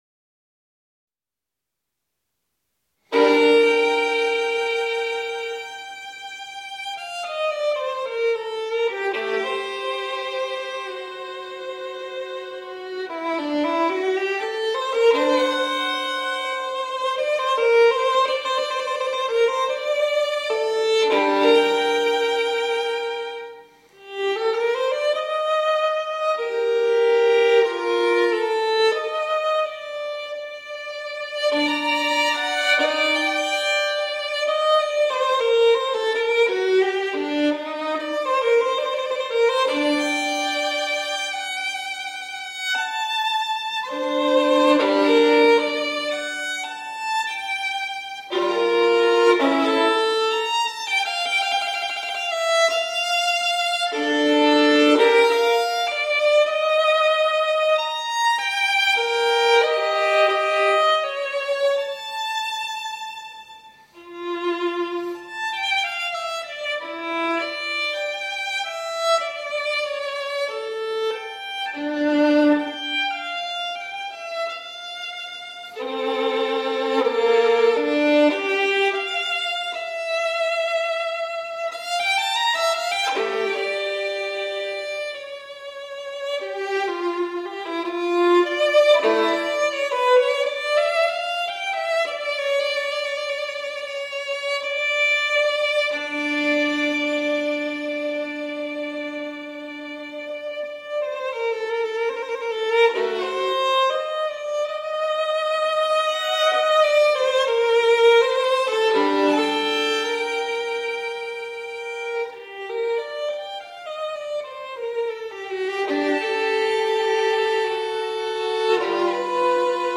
小提琴演奏
in G Minor/g-moll/sol mineur